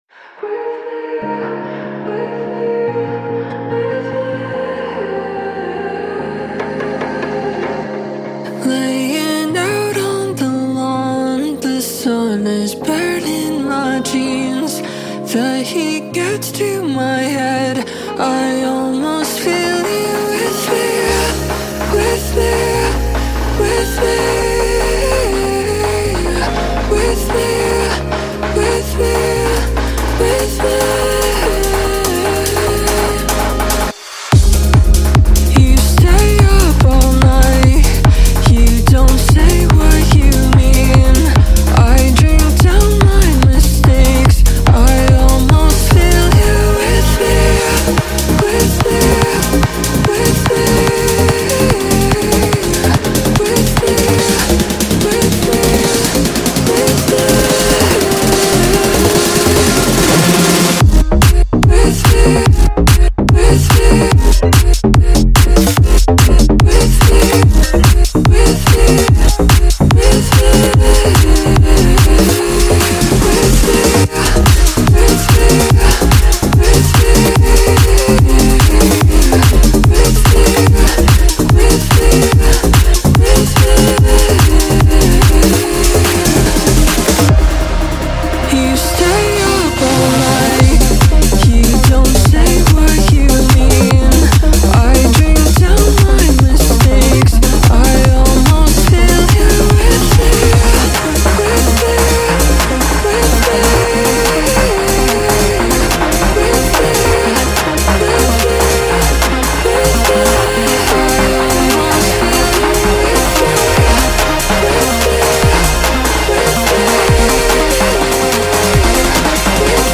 whose smooth and confident verse adds balance